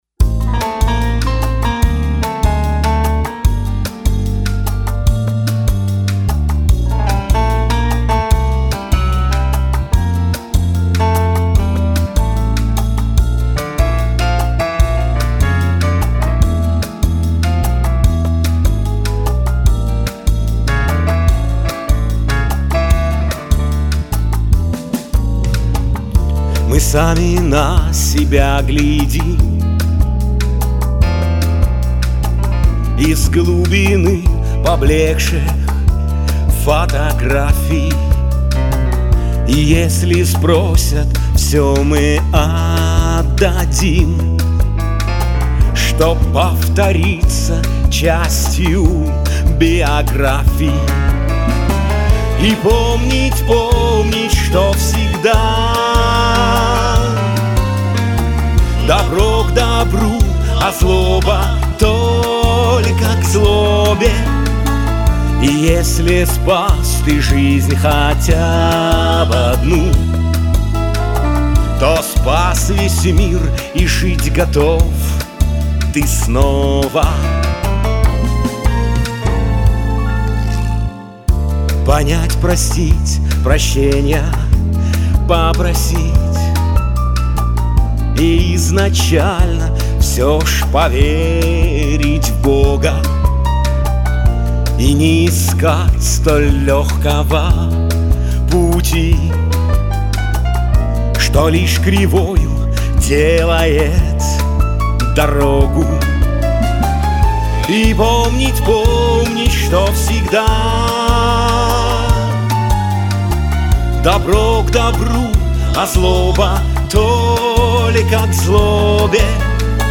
"ЗАПОВЕДИ" - новая песня.
вокал